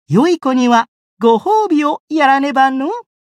觉醒语音 对好孩子必须奖励呢 良い子にはご褒美をやらねばのう 媒体文件:missionchara_voice_612.mp3